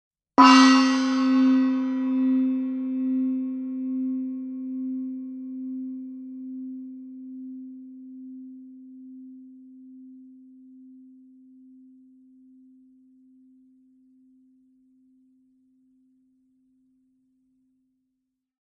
PLAYTECHの10インチゴング。